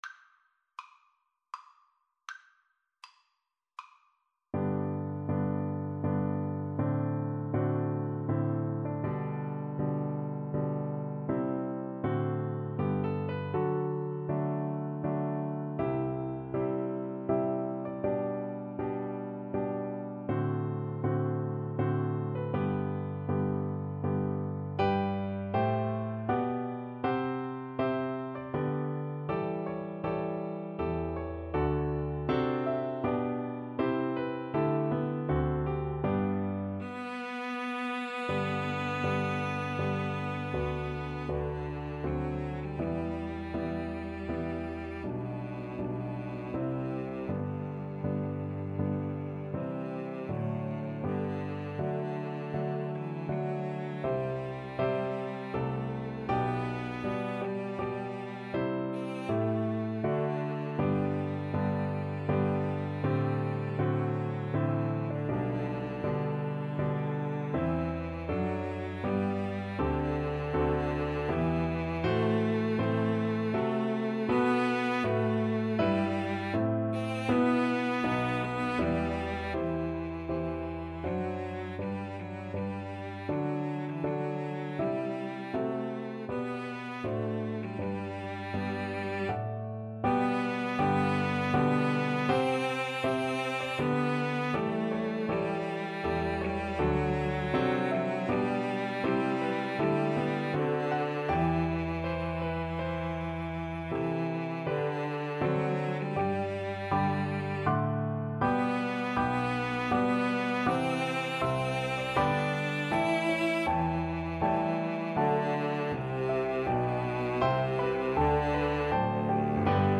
Free Sheet music for String trio
G major (Sounding Pitch) (View more G major Music for String trio )
Andante grandioso